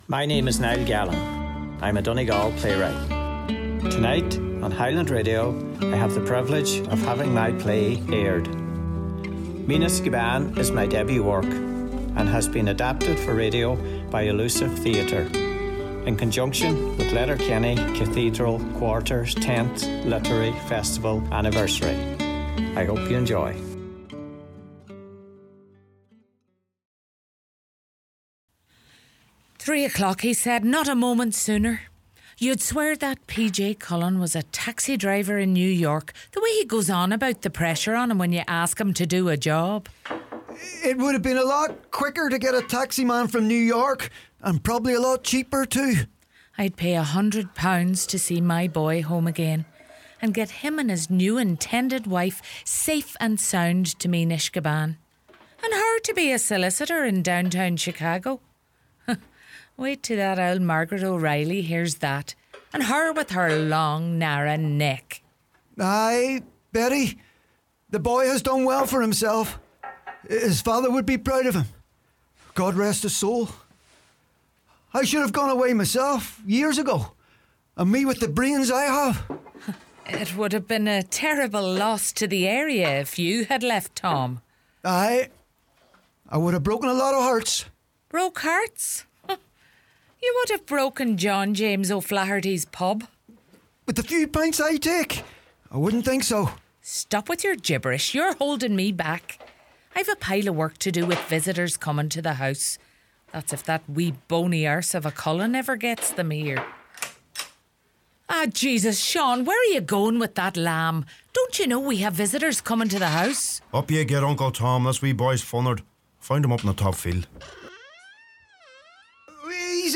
This special broadcast is brought to you by Elusive Theatre, in conjunction with the Letterkenny Cathedral Quarter 10th Anniversary celebrations.